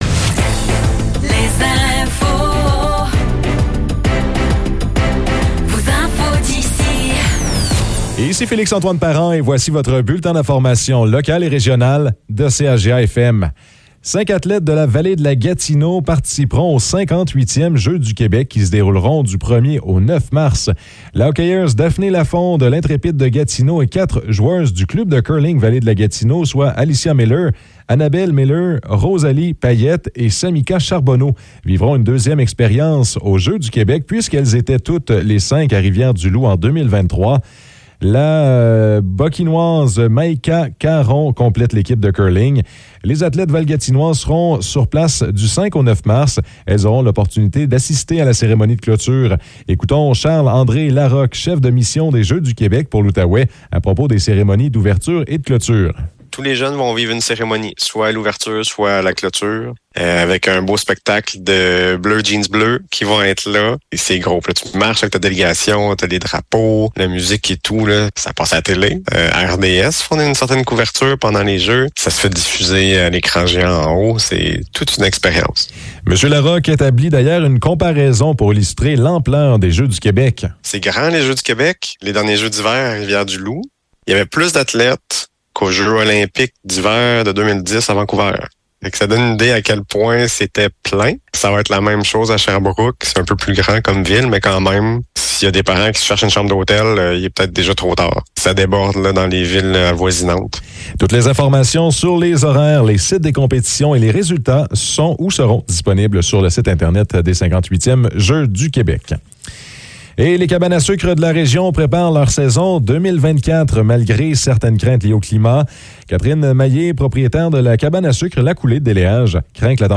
Nouvelles locales - 7 février 2024 - 15 h